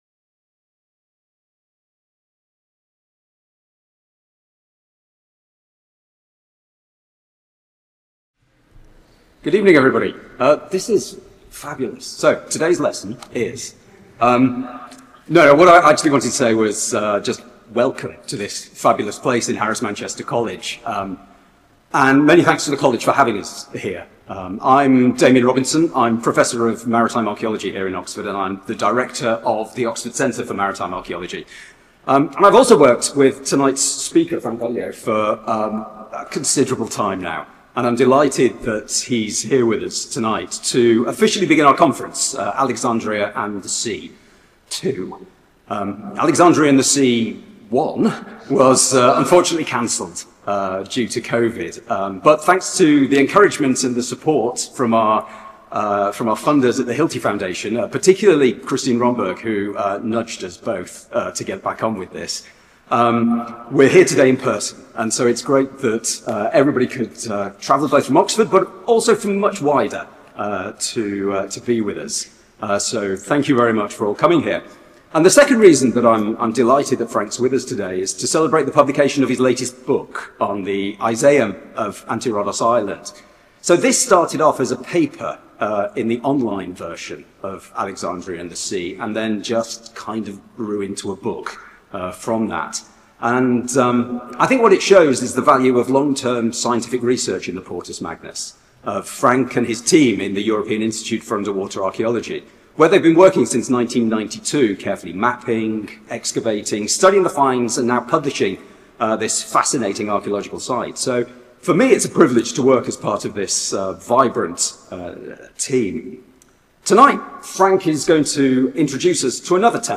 In this lecture Franck Goddio presents the research of the European Institute for Underwater Archaeology (IEASM) in the Portus Magnus and on this “arm” of land which stands out clearly on the topographical map obtained.